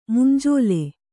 ♪ mumnjōle